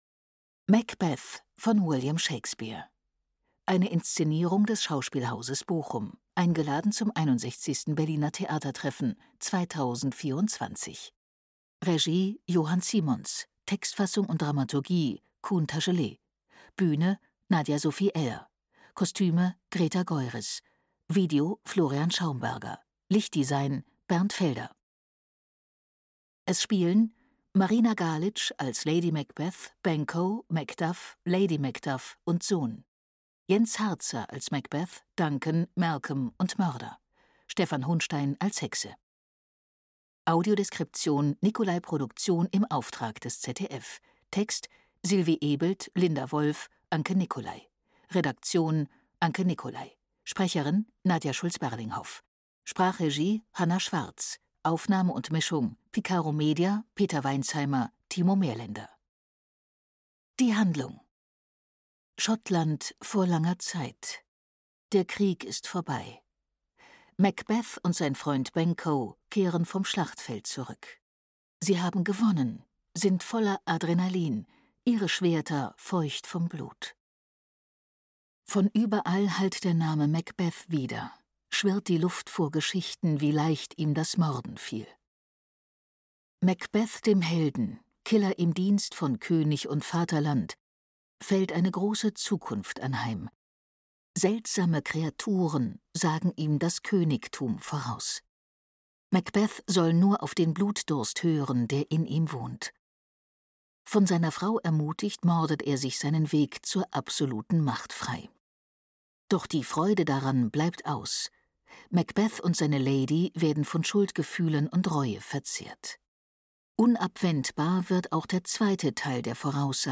Audiodeskriptive Einführung
tt24_audiodeskription_macbeth.mp3